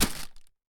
rustling hit effect 01
bonk effect fist hit impact rustling short thud sound effect free sound royalty free Sound Effects